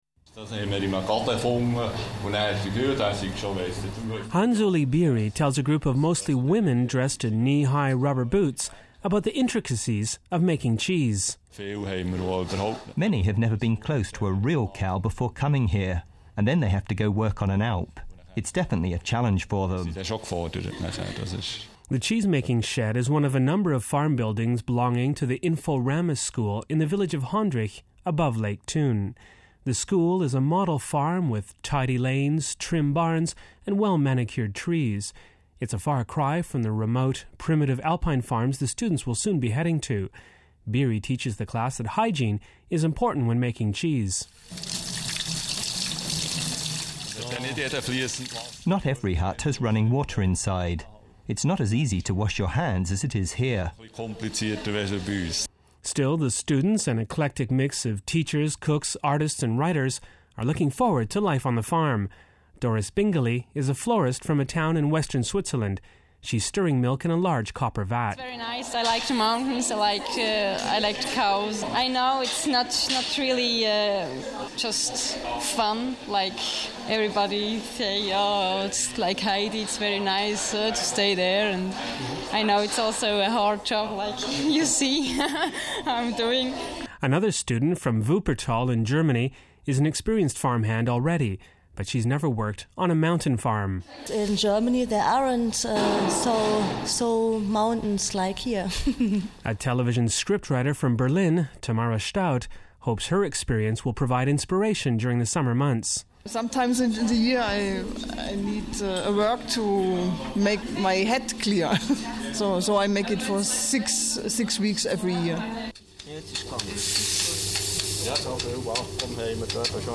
But in Switzerland, it’s the alpine farmers who are becoming extinct… and inexperienced city dwellers who are lending a hand. At a school in the Bernese Oberland, a group is preparing for a summer on an alpine farm by learning how to milk cows and make cheese.